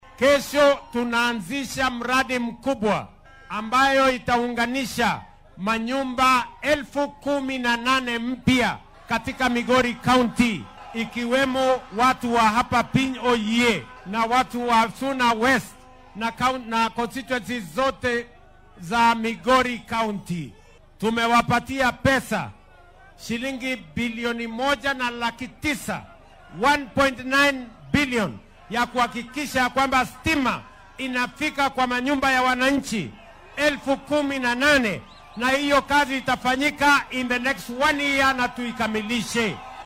Arrintan ayuu ka sheegay munaasabad kaniiseed oo maanta lagu qabtay ismaamulka Migori .